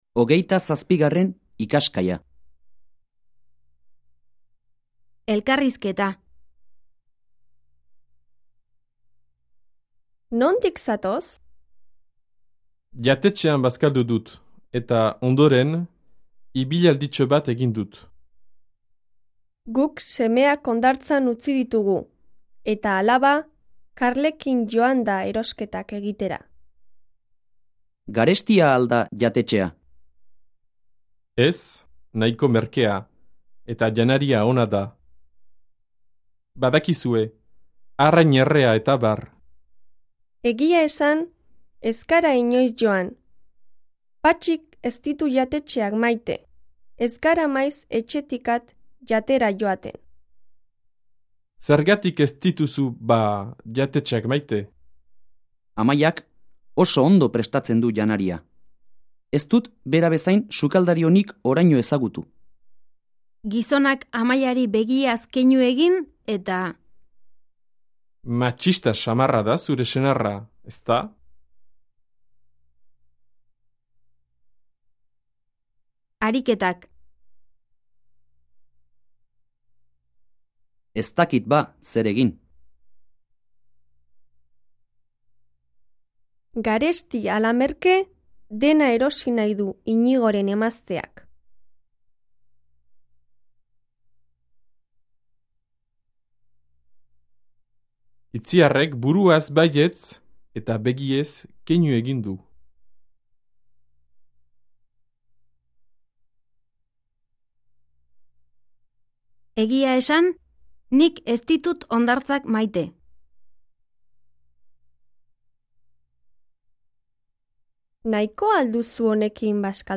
Диалог
1 yatetchean basskaldu dut eta ondoren ibillaldicho... 5 ess nayko merkea eta yanaria... etabar... 6 egia esan esskara... 7 частица bada часто произносится просто как ba